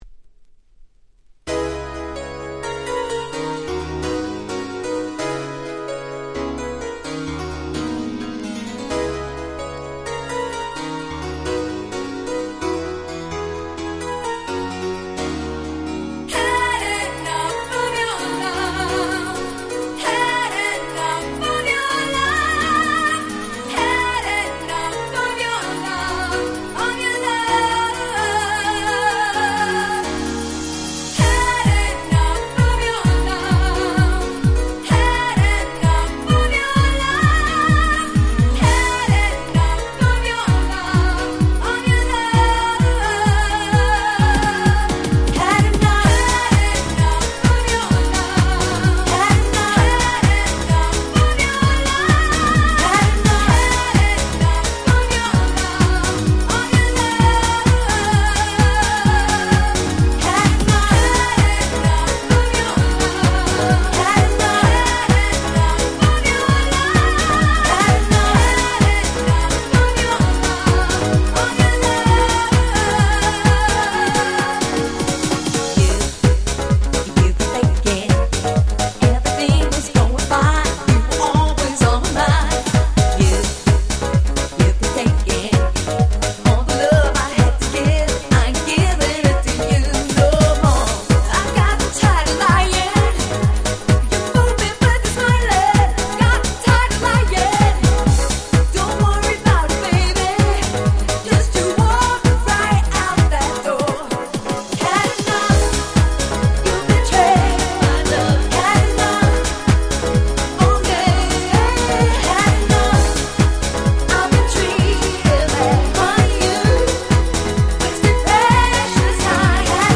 95' Super Nice Italo Vocal House !!
90年代哀愁系歌物ハウス好きにはど真ん中でしょう！！
イタロハウス